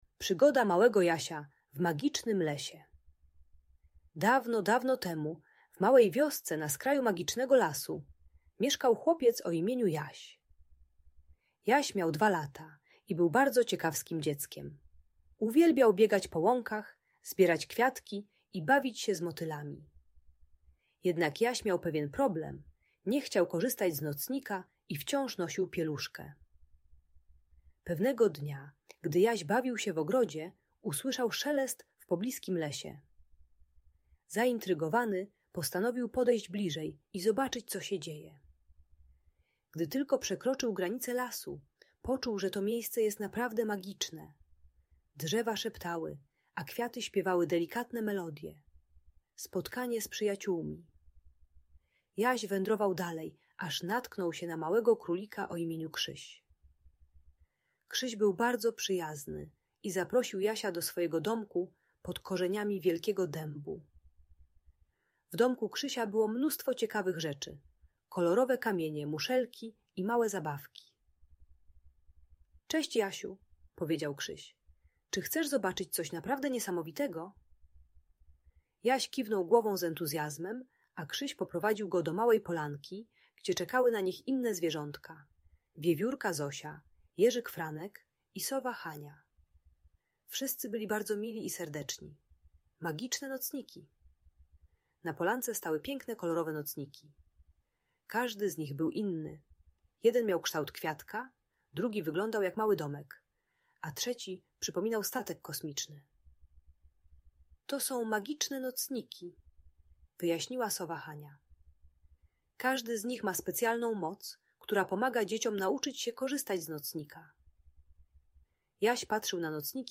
Przygoda Małego Jasia - Magiczna Opowieść - Trening czystosci | Audiobajka